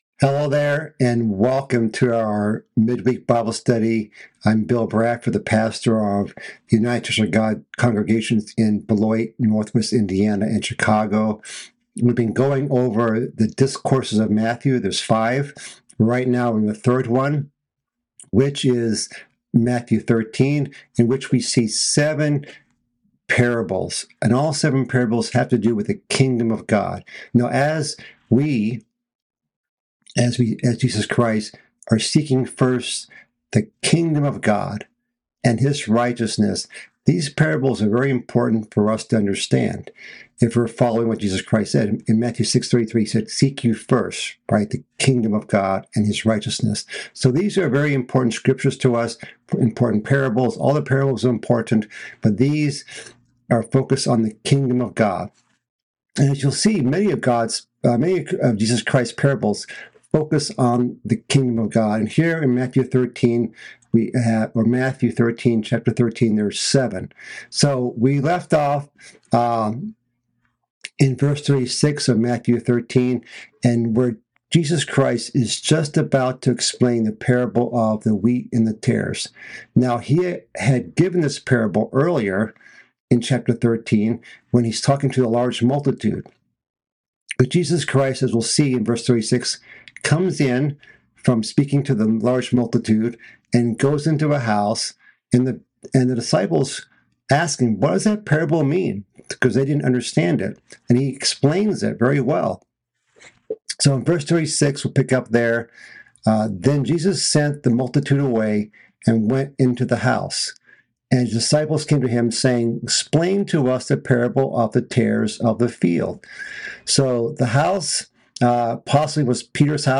This is a continuation of a mid-week Bible study series covering Christ's third discourse in the book of Matthew. This message continues in chapter 13 of Matthew, covering Christ's explanation of the parable of the weeds.